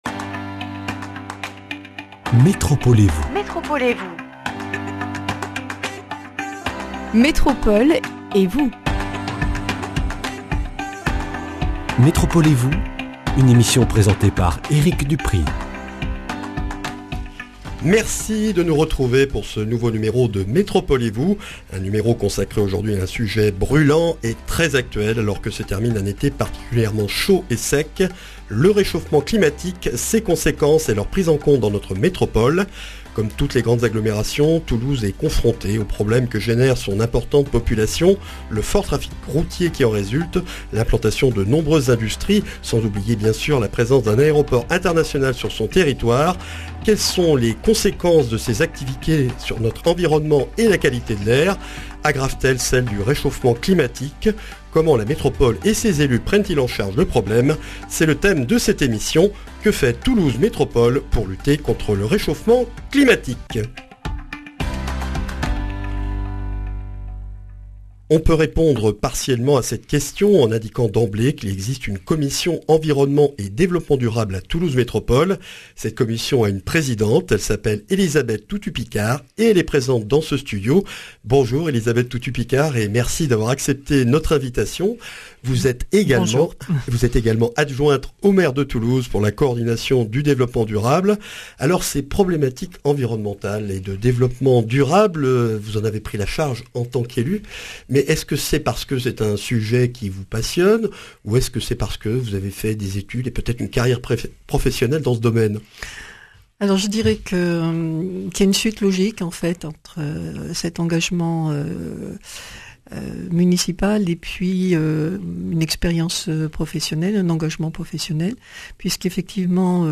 Une émission avec Élisabeth Toutut-Picard, adjointe au maire de Toulouse pour la coordination de la politique de Développement durable, Présidente de commission de Toulouse Métropole - Environnement et Développement durable, pour comprendre les enjeux du Plan climat 2020 et les mesures prises par la Métropole pour lutter contre le réchauffement climatique.
Speech